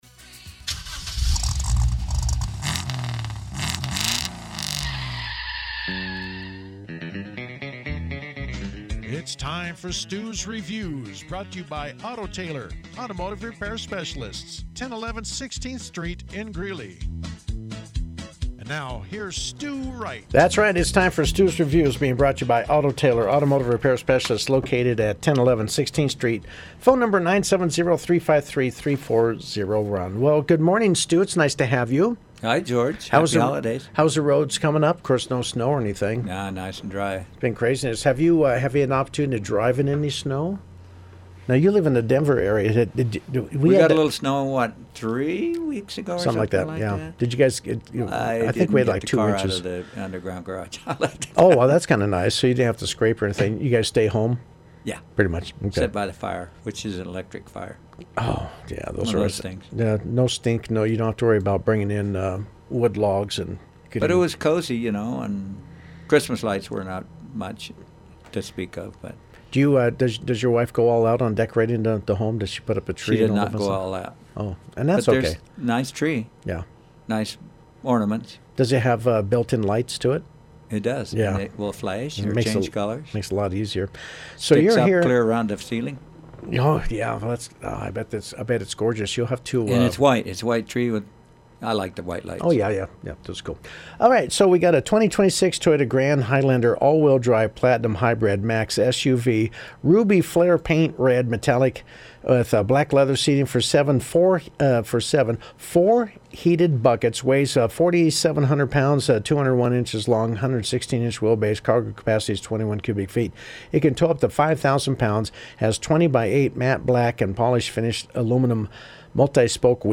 The Grand Highlander was driven to Pirate Radio in Greeley for a review: